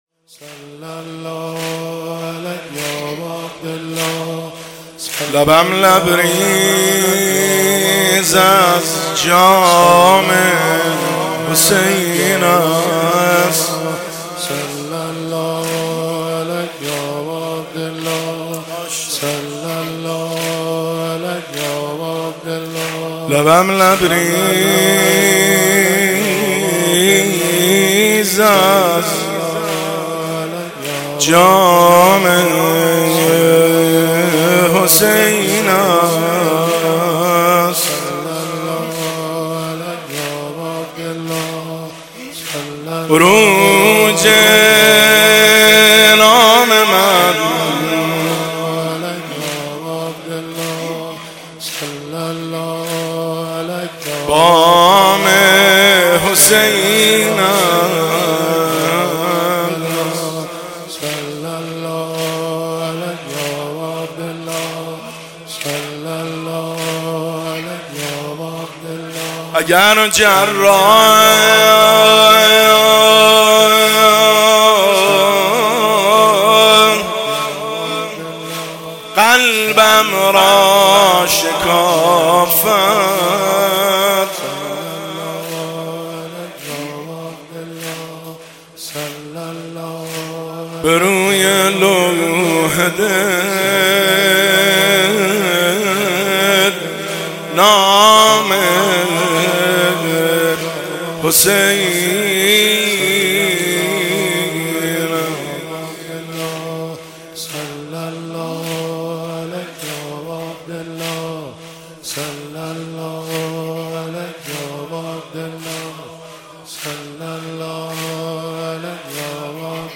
زمزمه – شب دوم محرم الحرام 1401